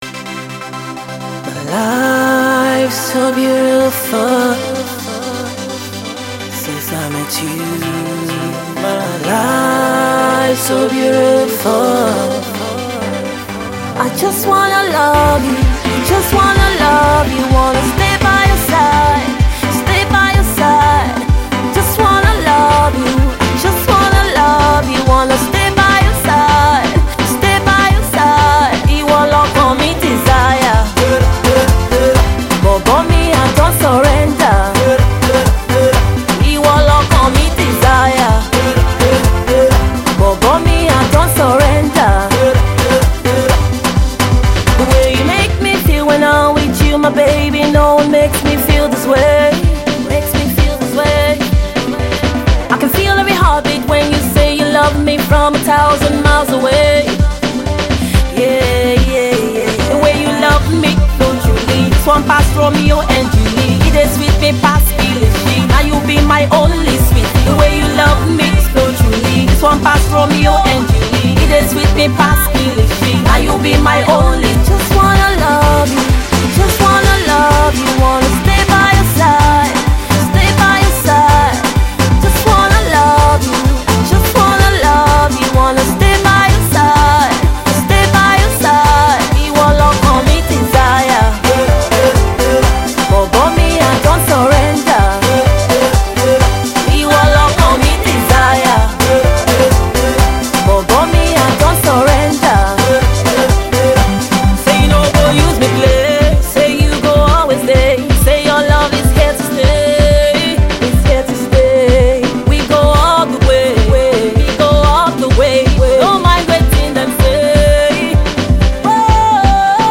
Afro Pop
What do you think about her new Pop music?